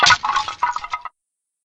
lose_a_life.ogg